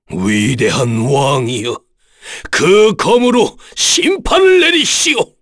Dakaris-Vox_Skill1_long_kr_b.wav